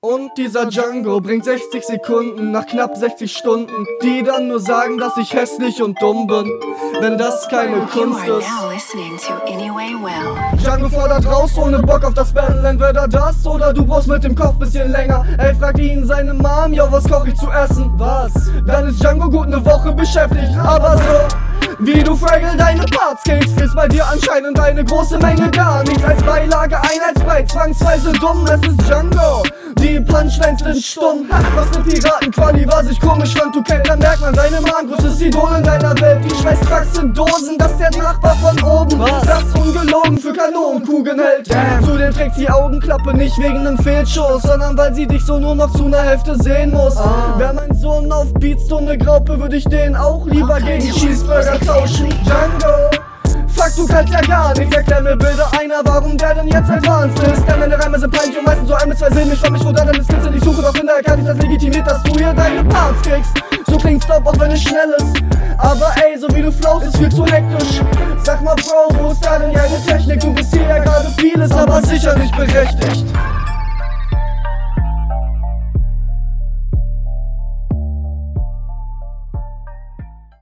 Im Intro ist deine Mische bisschen besser als sonst, im Part allerdings wieder dumpf.